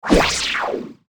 academic_skill_tower_01_throw.ogg